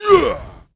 client / bin / pack / Sound / sound / monster / barbarian_boss / attack_1.wav
attack_1.wav